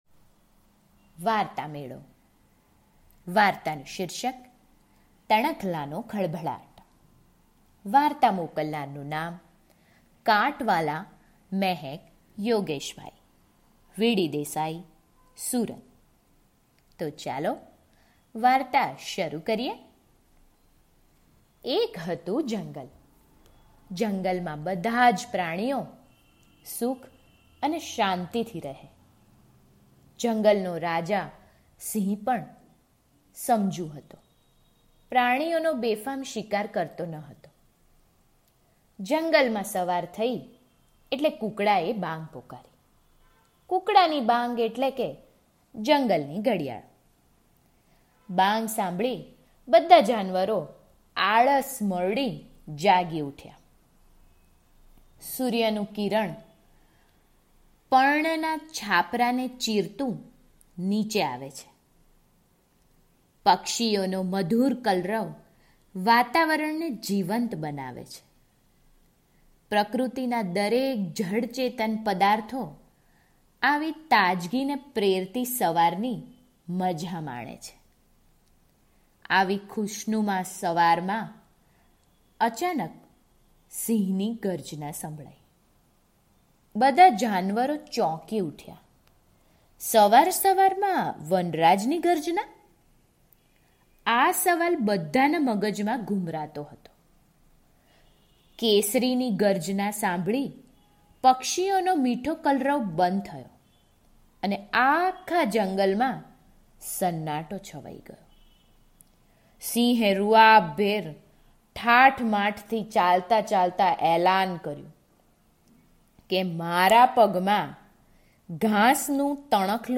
તણખલા નો ખળભળાટ - ઓડિયો વાર્તા - વાર્તામેળો